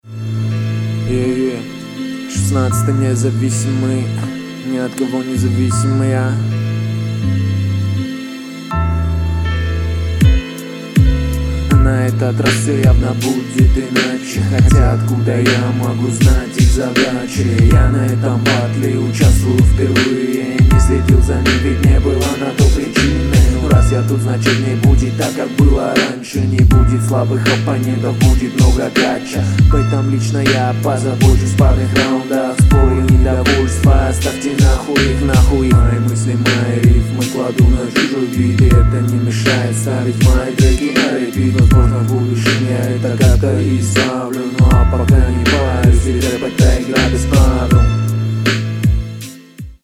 Подача слабая.
Читка ленивая, текст тоже ленивый.